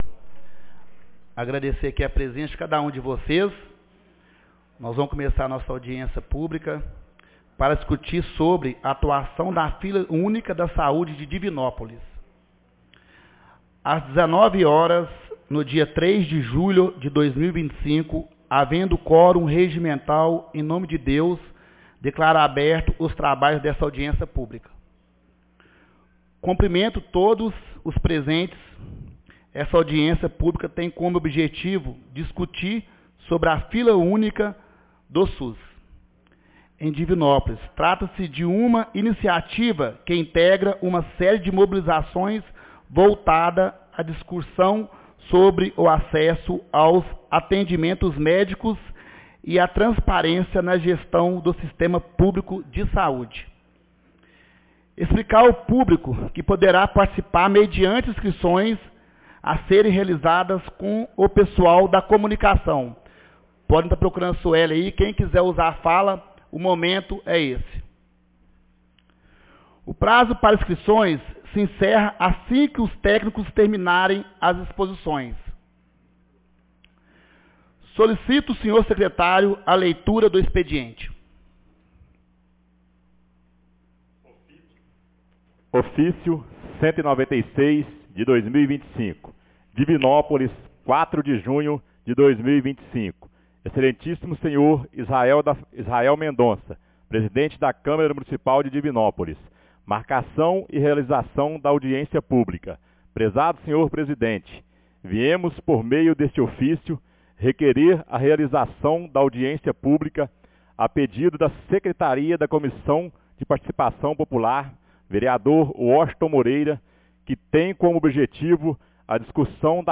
Audiencia Publica Fila Unica 02 de julho de 2025